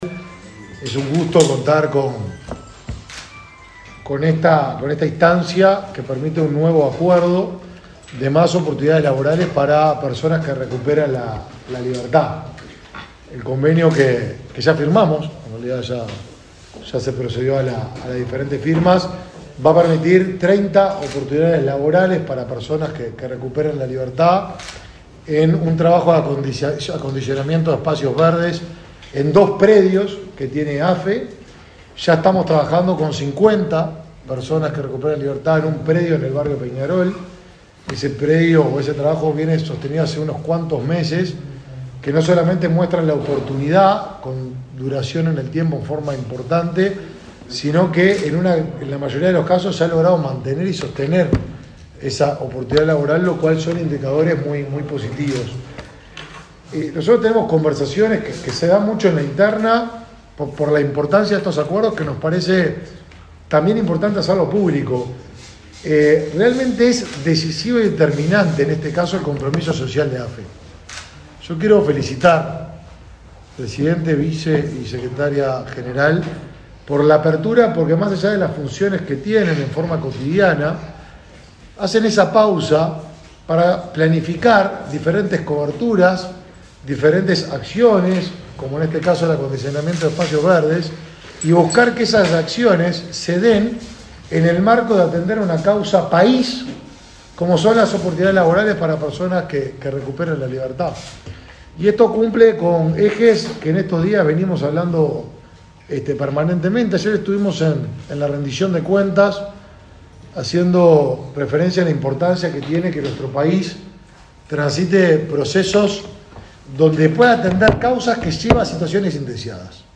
Palabras de autoridades en convenio entre el Mides y AFE
El Ministerio de Desarrollo Social (Mides) firmó, a través de la Dirección Nacional de Apoyo el Liberado, un acuerdo con AFE, a fin de que personas que recuperan la libertad realicen tareas de limpieza y desmalezamiento de áreas verdes en talleres del ente. Los titulares del Mides, Martín Lema, y AFE, José Pedro Pollak, destacaron la importancia del convenio.